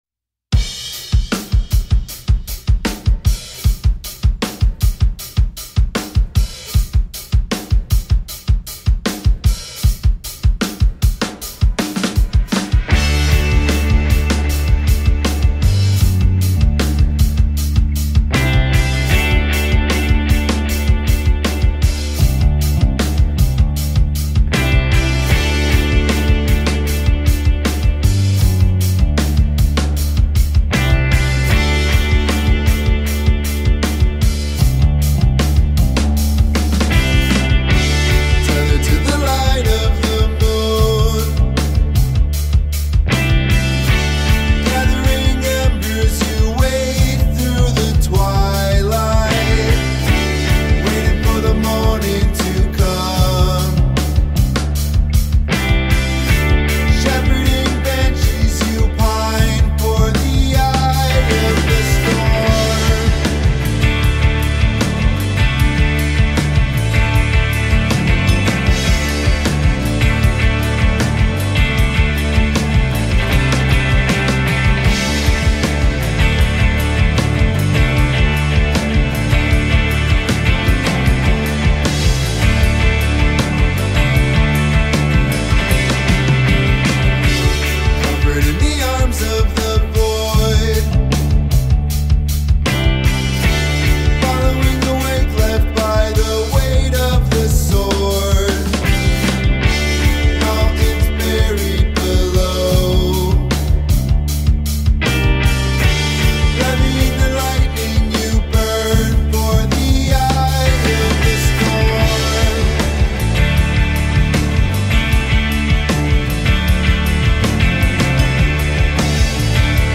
DOOM MUSIC